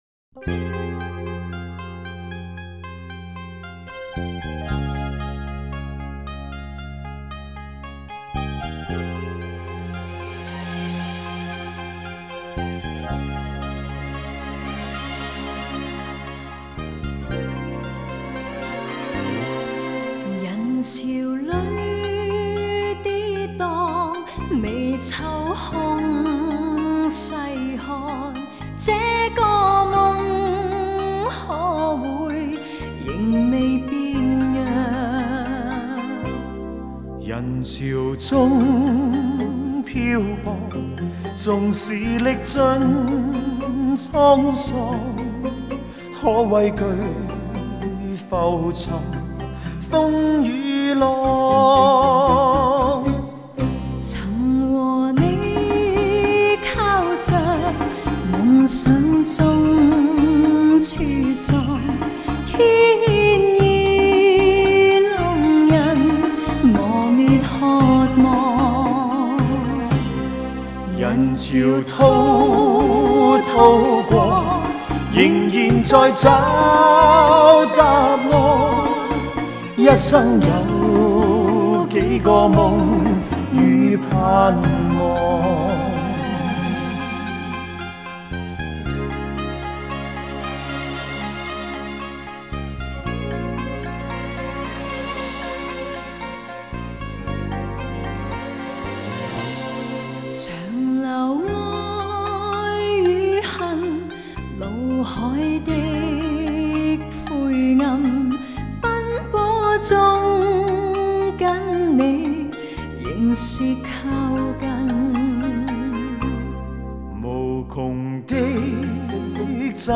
是首节奏强劲的快歌